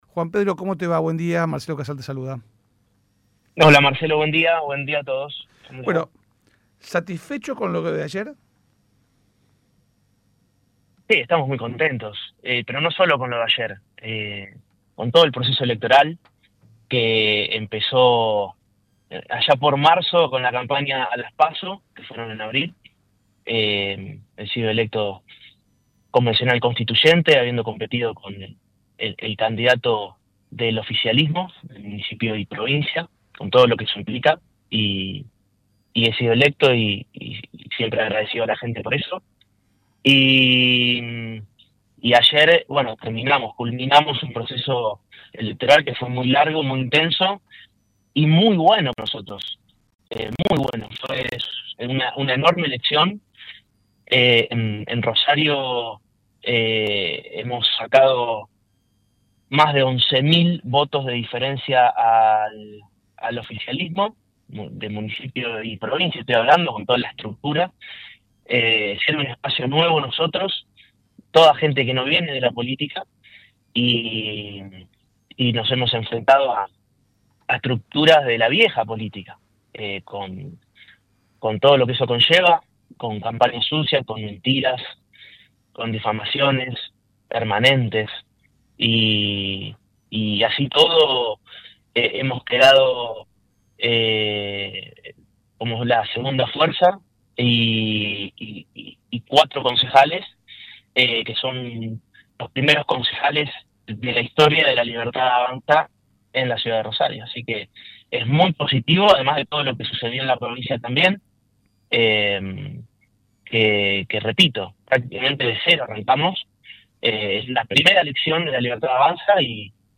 El flamante concejal de La Libertad Avanza, Juan Pedro Aleart habló en el programa La Barra de Casal para analizar los resultados de las elecciones que lograron 4 nuevos concejales para su espacio en el Concejo de Rosario en su primera elección en la ciudad.